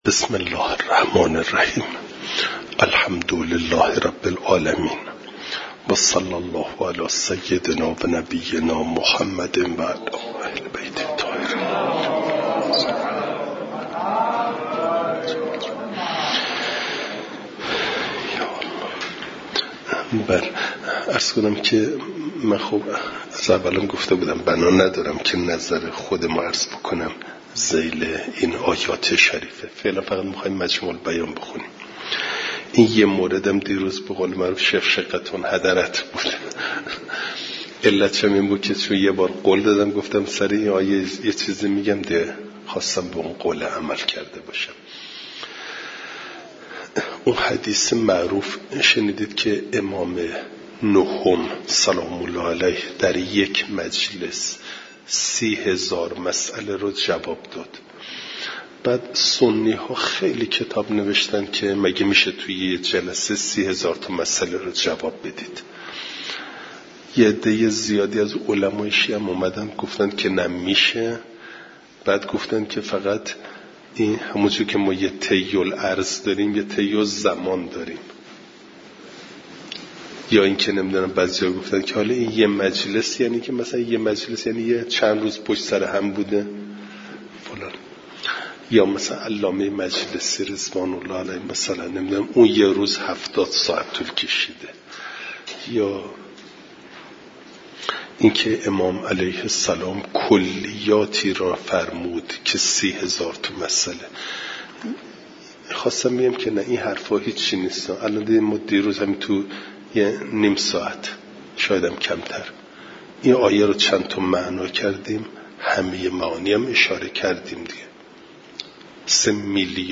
فایل صوتی جلسه صد و بیست و هفتم درس تفسیر مجمع البیان